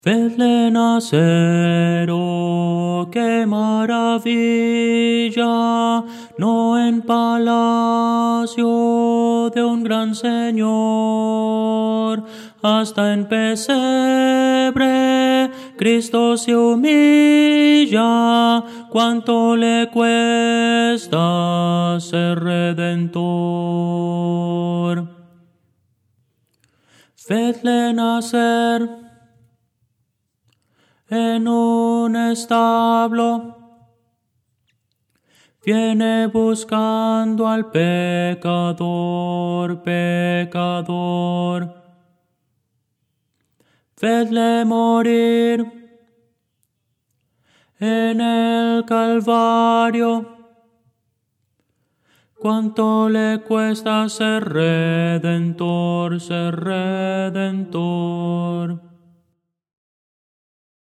Voces para coro
Tenor – Descargar
Audio: MIDI